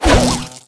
jess_throw_01.wav